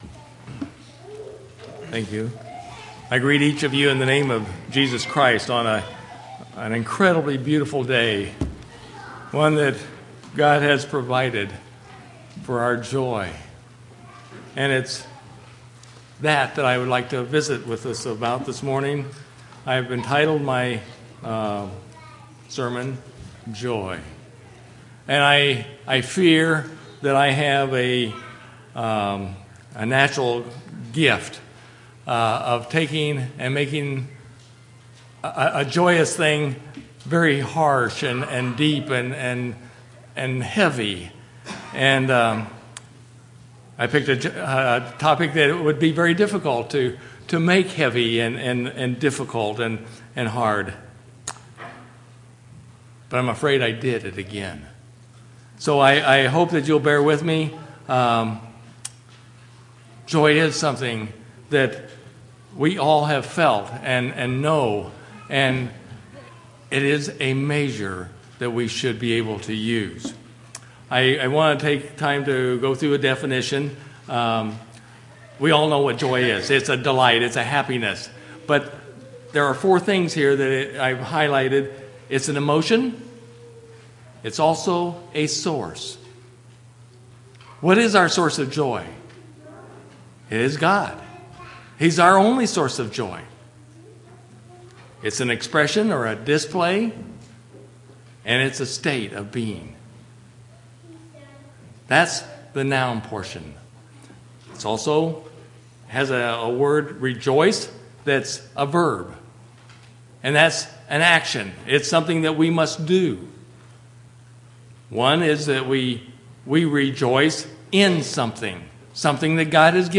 5/15/2016 Location: Temple Lot Local Event